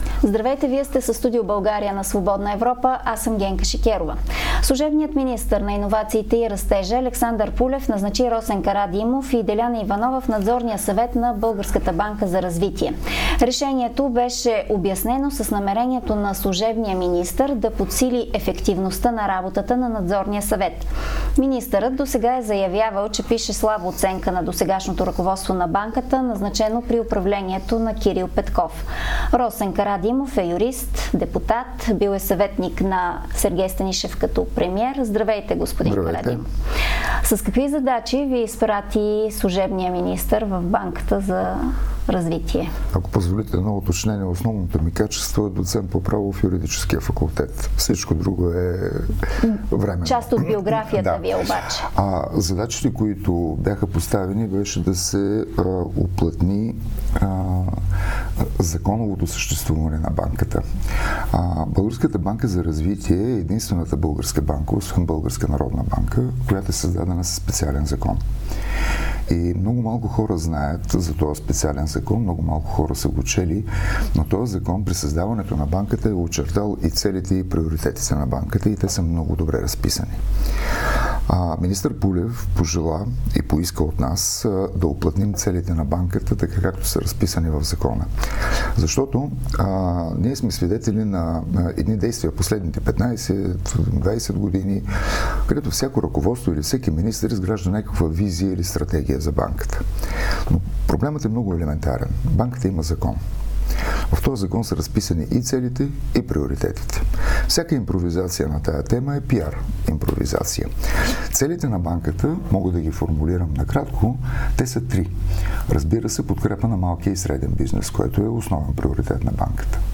Това каза депутатът от "Демократична България" Божидар Божанов в Студио България.